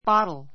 bottle 中 A1 bɑ́tl バ トる ｜ bɔ́tl ボ トる 名詞 瓶 びん ; 1瓶の量 ⦣ ふつう口が狭 せま く, 取っ手の無いもの.